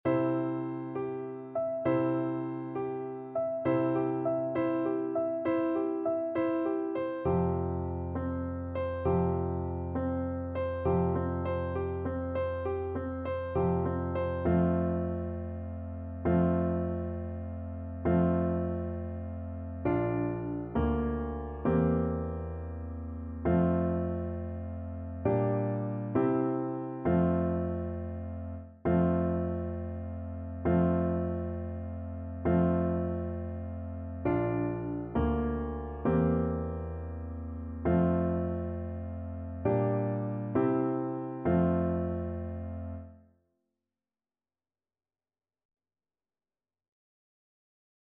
Allegretto
Classical (View more Classical French Horn Music)